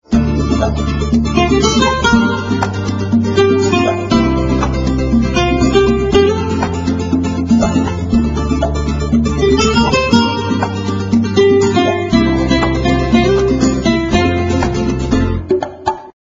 圣诞歌曲